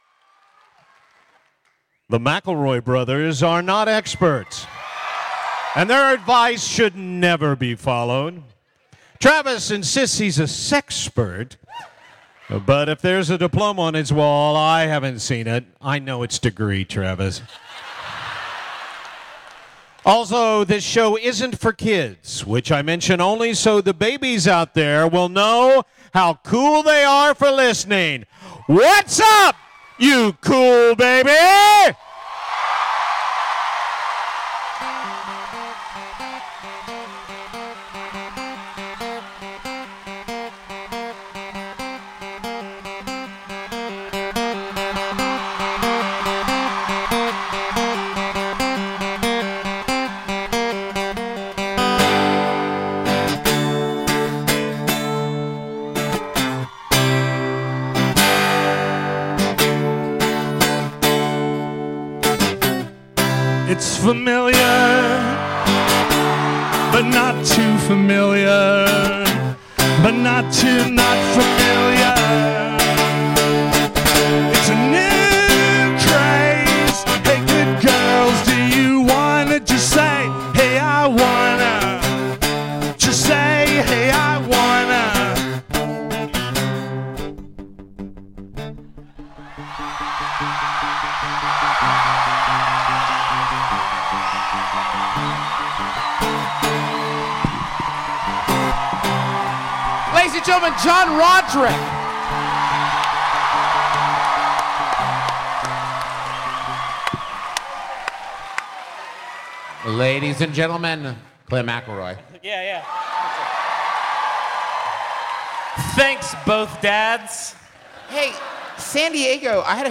Here’s our live show from the beautiful Balboa Theater in also-beautiful San Diego!